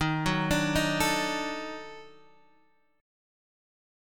EbmM7b5 Chord